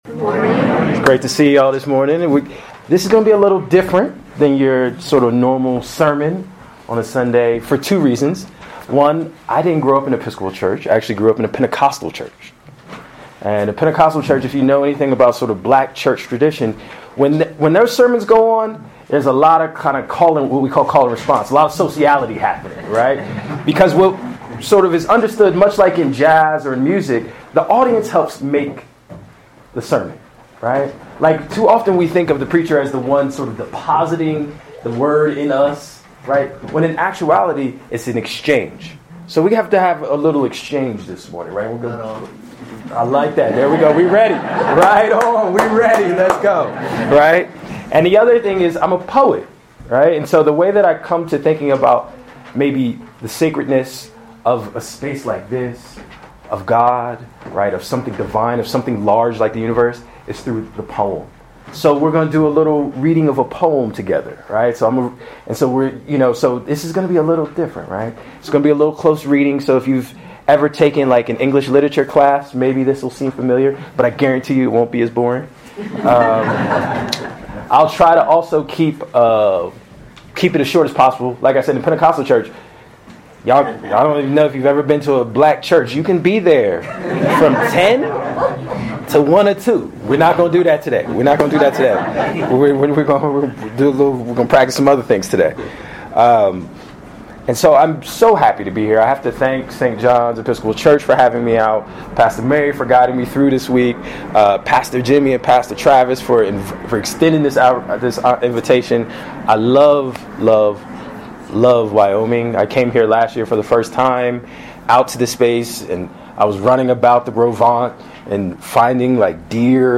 Sermons from St. John's Episcopal Church Chapel of the Transfiguration Proper 10